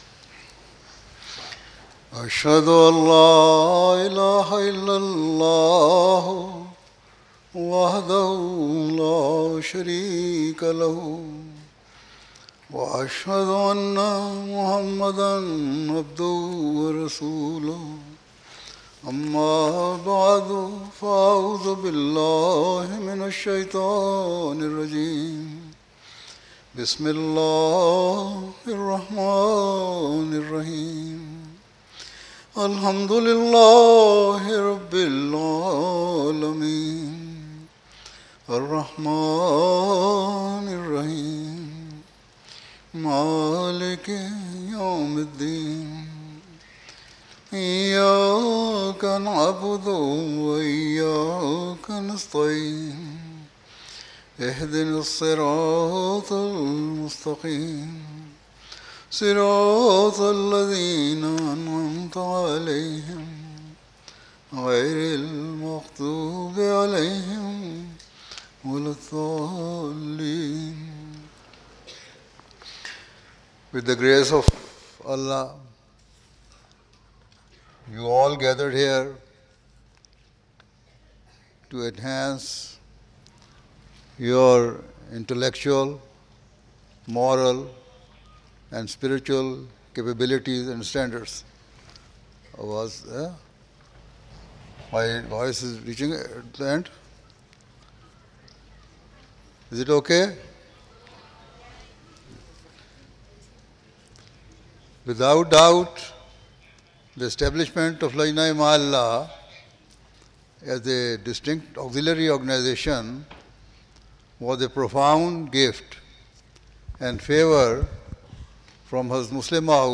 English addresses delivered by Hazrat Khalifatul Masih, worldwide head of Ahmadiyya Muslim Community at various occasions.